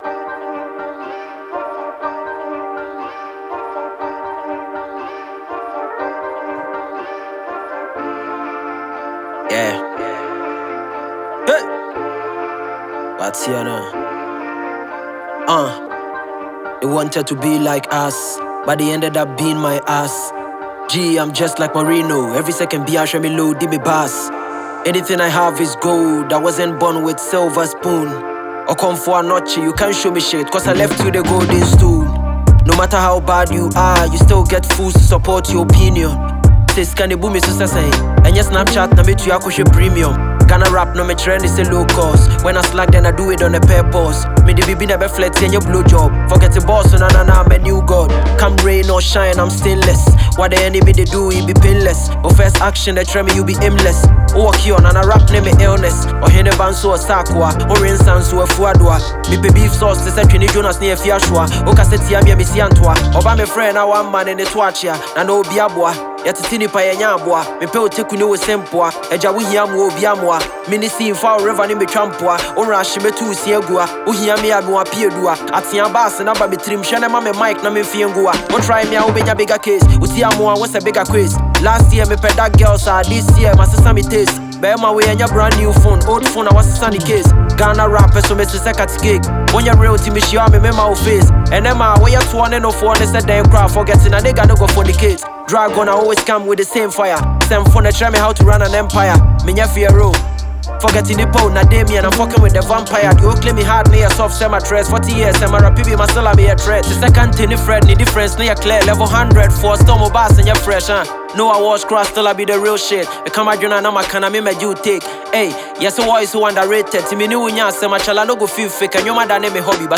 Multiple Award-winning rapper